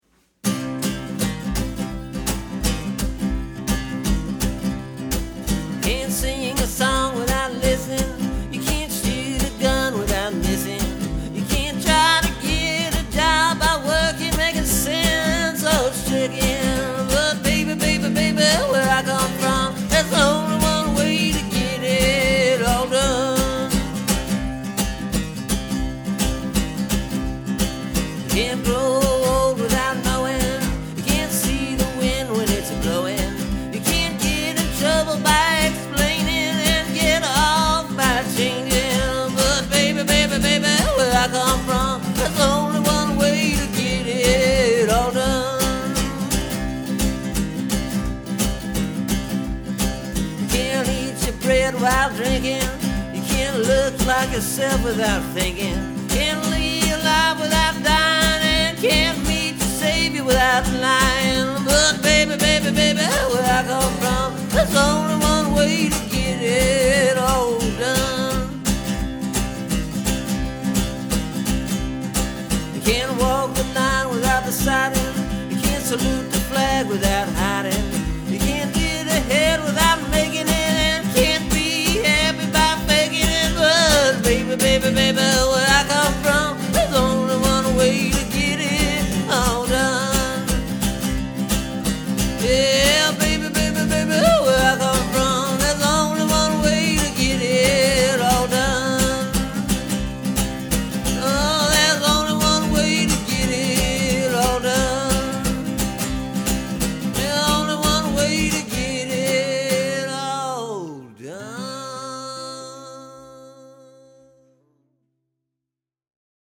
It’s a patriotic tune.
I combined some of the verses to make new sets of verses and then used the repeating element from the original to craft together a chorus.